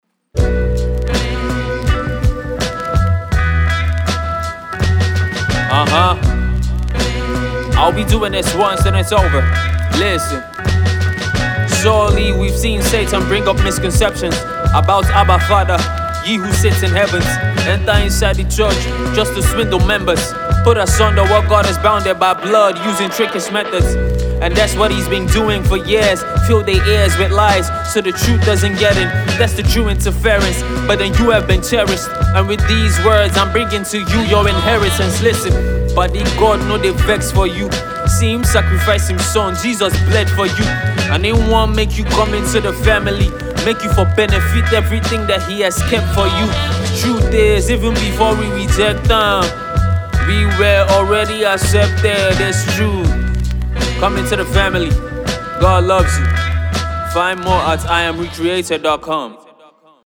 rap piece